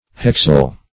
Search Result for " hexyl" : The Collaborative International Dictionary of English v.0.48: Hexyl \Hex"yl\, n. [Hex- + -yl.]